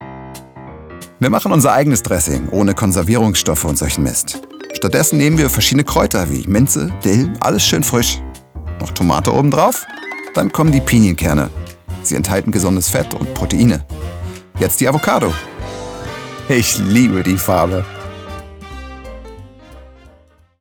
dunkel, sonor, souverän, plakativ
Mittel plus (35-65)
Voice Over 03 - Kochen
Audioguide, Comment (Kommentar), Doku, Narrative, Off, Overlay